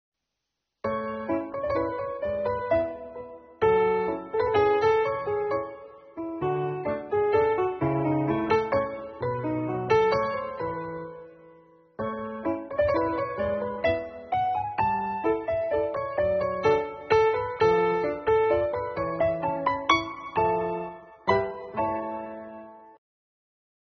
活动现场集锦